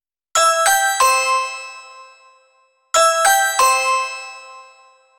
Ring Doorbell Notifications.ogg